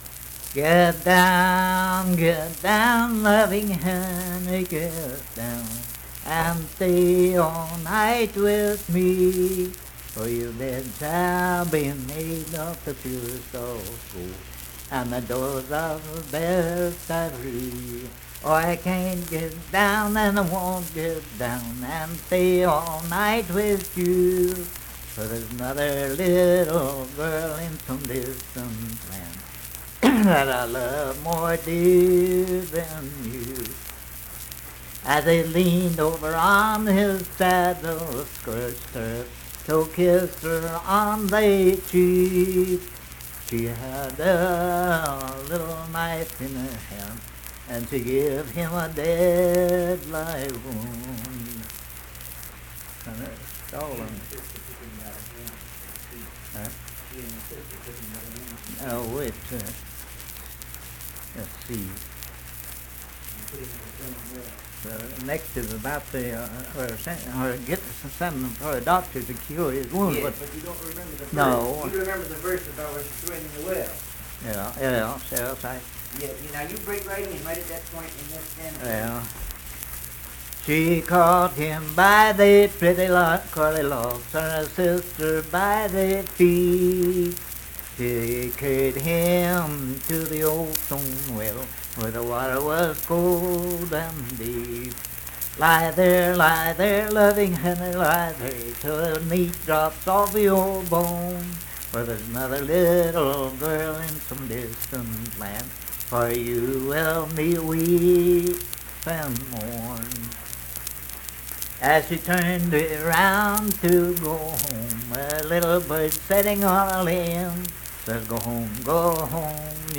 Unaccompanied vocal music
Voice (sung)
Huntington (W. Va.), Cabell County (W. Va.)